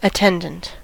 attendant: Wikimedia Commons US English Pronunciations
En-us-attendant.WAV